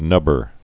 (nŭbər)